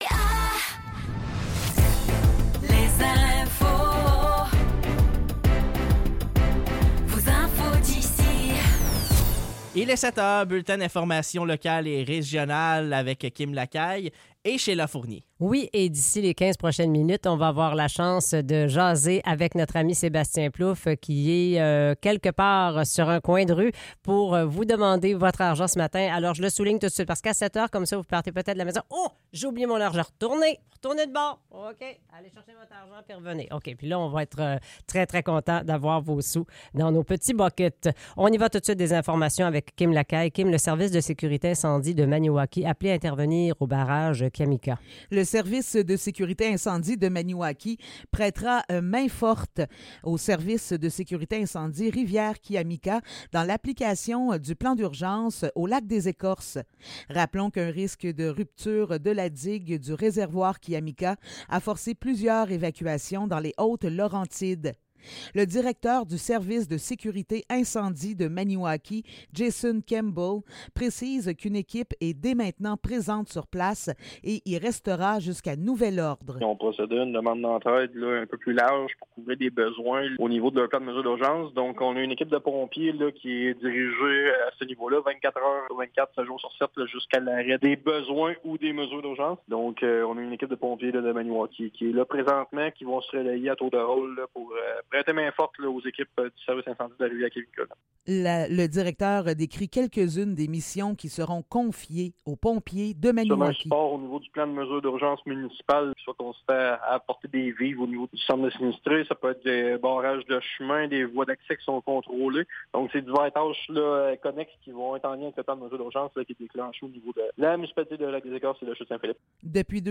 Nouvelles locales - 7 décembre 2023 - 7 h